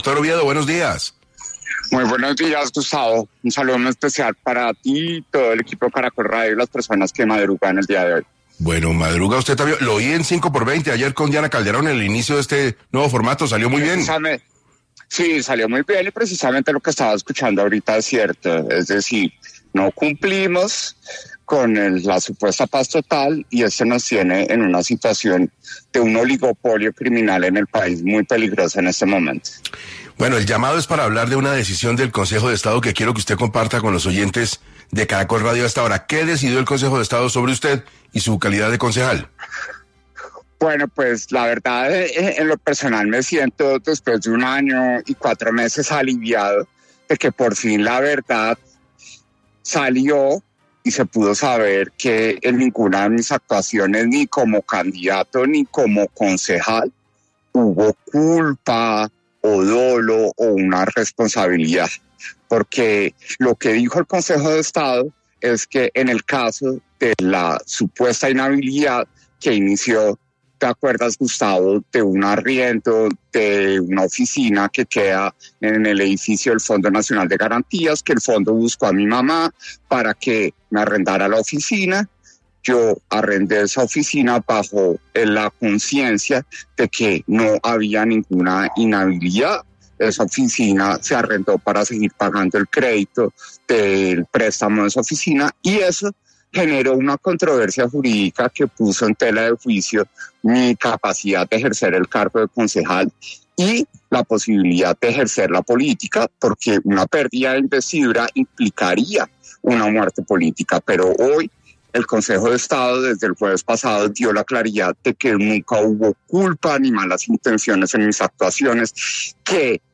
En entrevista con 6AM, Juan Daniel Oviedo, habló de esta decisión, pero también se refirió a la intervención que tuvo el presidente Gustavo Petro, en Naciones Unidas.